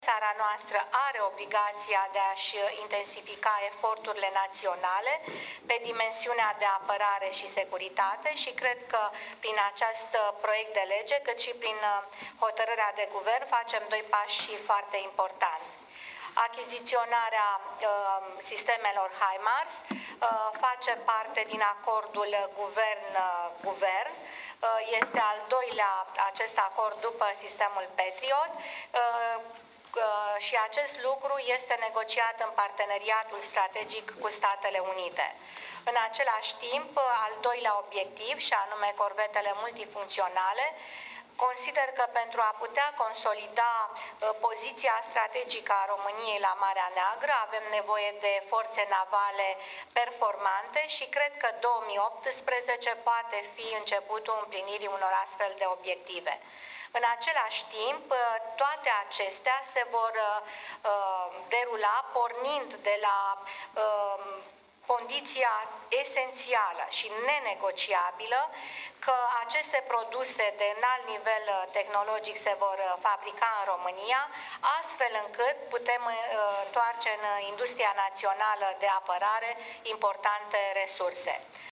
În deschiderea ședinței de Guvern, premierul Viorica Dăncilă a vorbit despre proiectul de lege pentru achiziţionarea sistemelor de rachete HIMARS şi hotărârea referitoare la corvetele multifuncţionale – documente care vor fi aprobate astăzi.